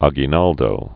A·gui·nal·do
gē-näldō), Emilio 1869-1964.